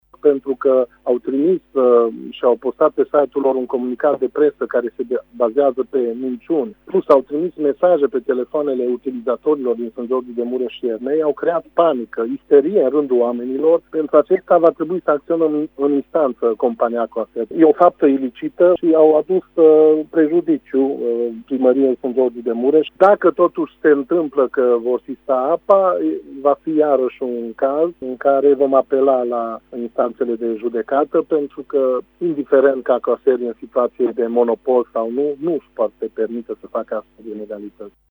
Primarul Sofalvi Szabolcs: